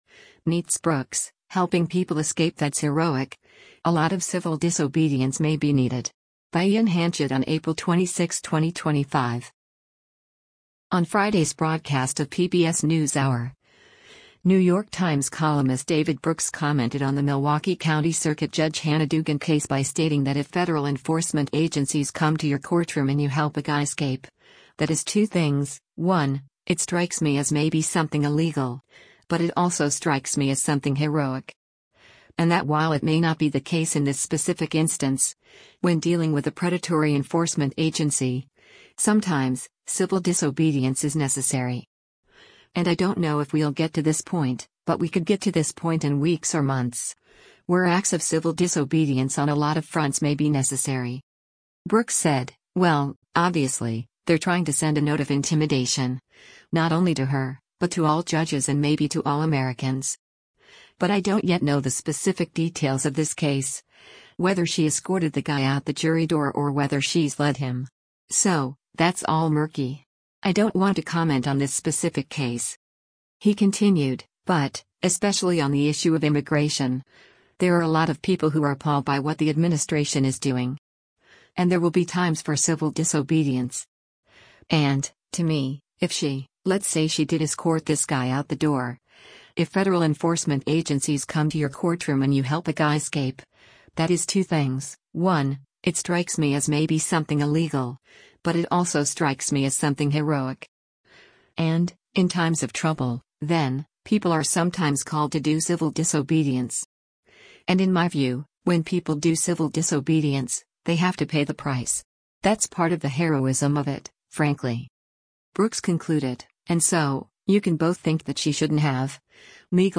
On Friday’s broadcast of “PBS NewsHour,” New York Times columnist David Brooks commented on the Milwaukee County Circuit Judge Hannah Dugan case by stating that “if federal enforcement agencies come to your courtroom and you help a guy escape, that is two things: One, it strikes me as maybe something illegal, but it also strikes me as something heroic.” And that while it may not be the case in this specific instance, when dealing with “a predatory enforcement agency, sometimes, civil disobedience is necessary.